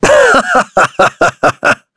Shakmeh-Vox_Happy4.wav